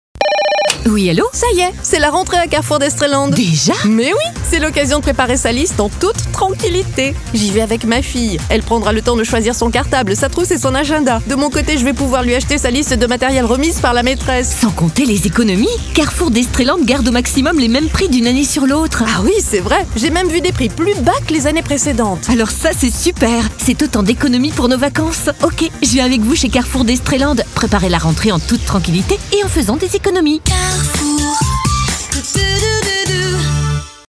Enregistrement voix off et montage sur musique !